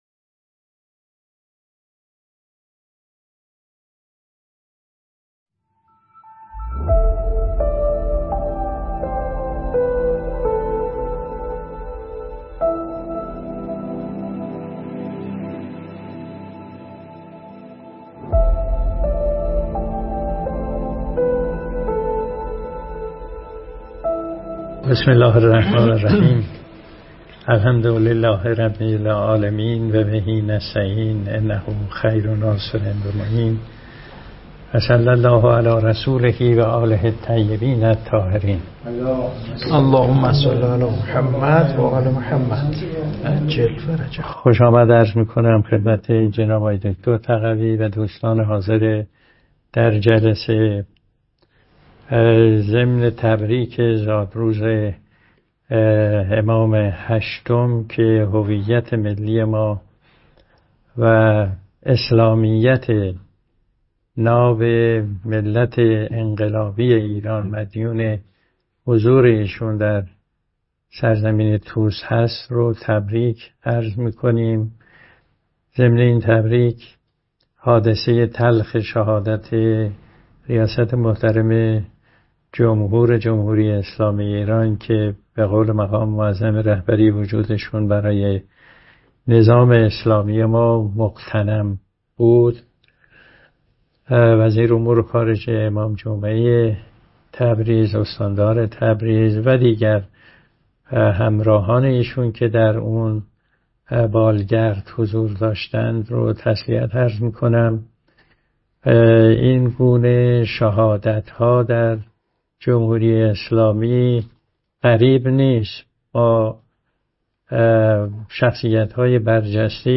در سالن طالقانی، دانشکده اقتصاد، مدیریت و علوم اجتماعی برگزار گردید.